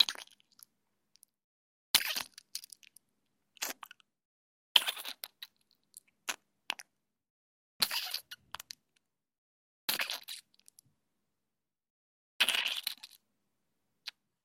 На этой странице собраны звуки, связанные с использованием шампуня: вспенивание, нанесение на волосы, смывание водой. Эти успокаивающие шумы подойдут для релаксации, ASMR-записей или фонового сопровождения.
Звук выдавливания шампуня на ладонь